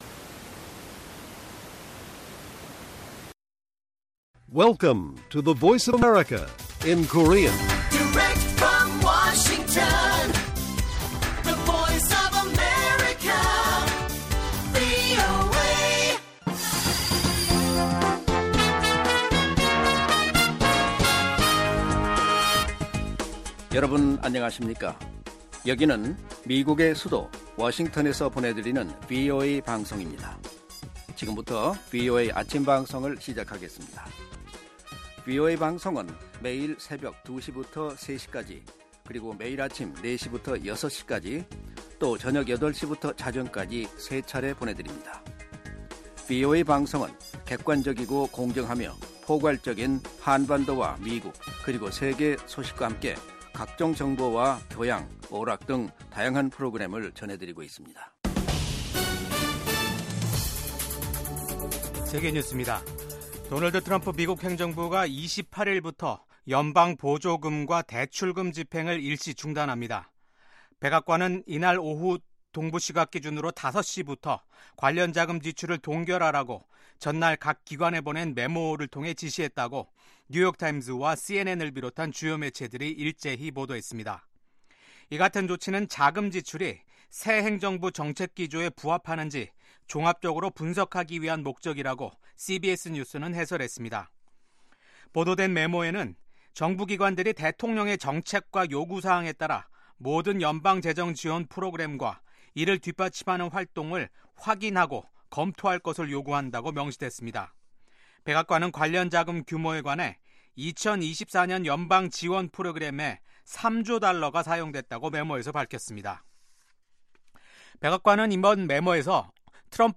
세계 뉴스와 함께 미국의 모든 것을 소개하는 '생방송 여기는 워싱턴입니다', 2025년 1월 29일 아침 방송입니다.